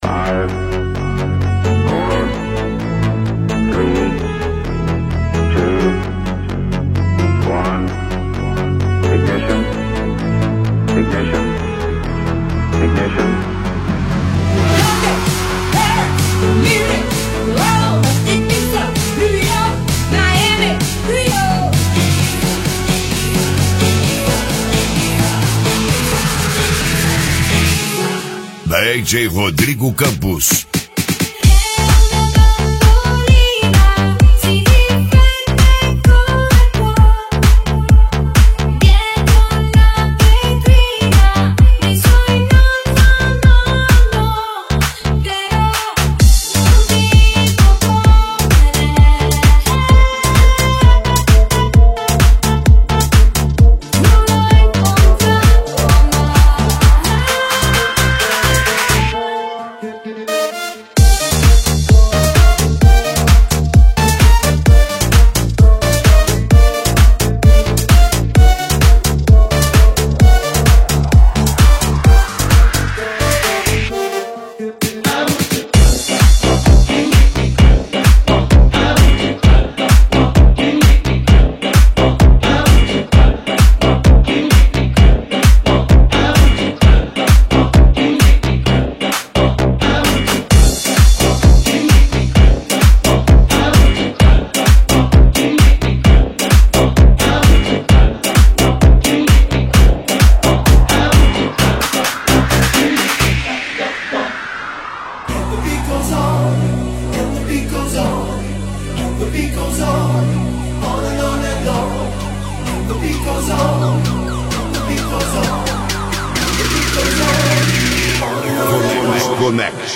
recheado de Retrô Remix